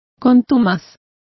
Complete with pronunciation of the translation of obdurate.